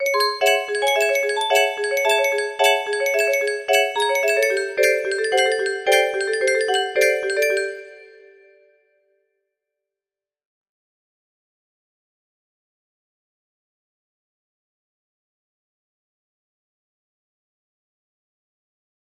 ronan music box melody